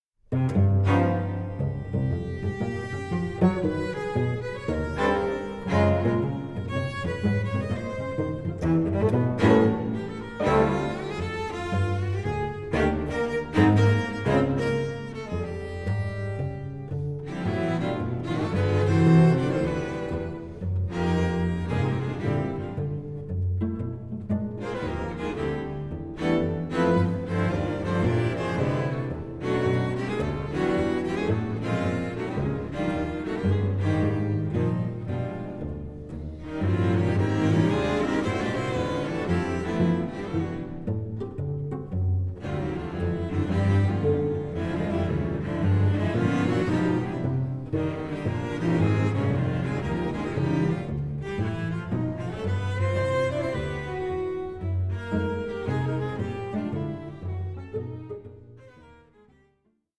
bebop